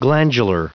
Prononciation du mot : glandular